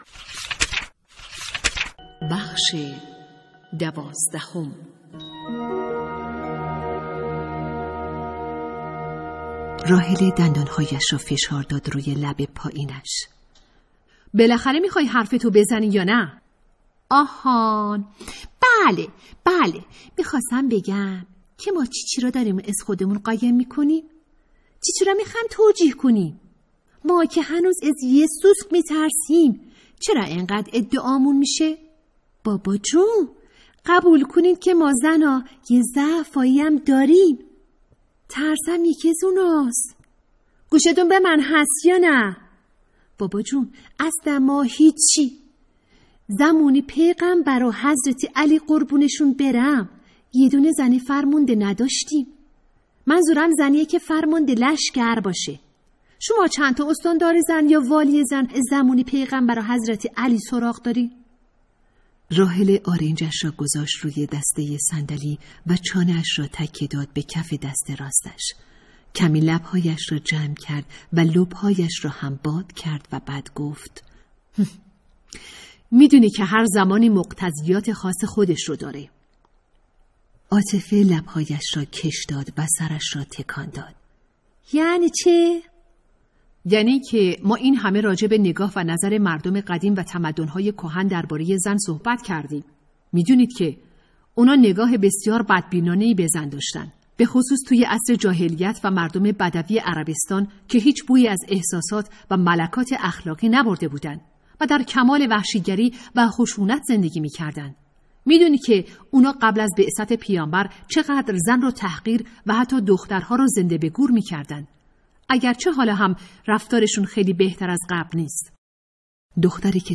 کتاب صوتی | دختران آفتاب (12)